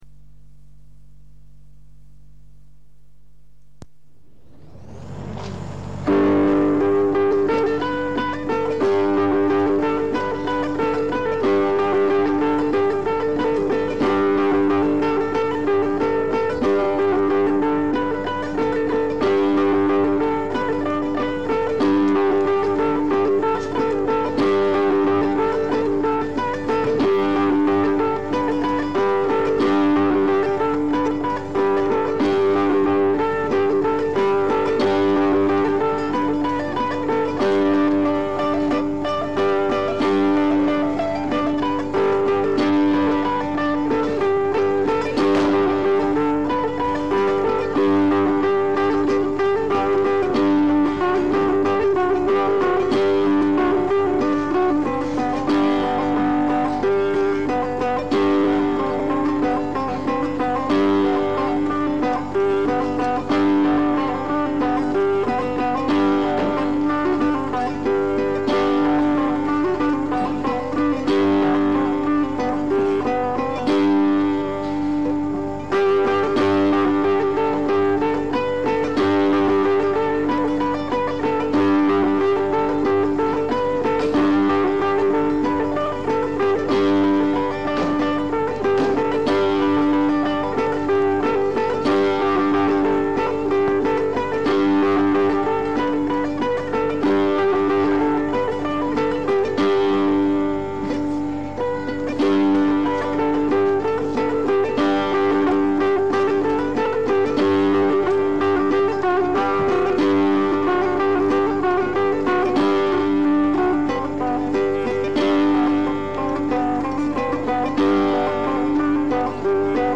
Sapeh (three-stringed boat lute)
From the sound collections of the Pitt Rivers Museum, University of Oxford, being one of a small number of recordings of the musical instruments in the institution's collections being played or discussed.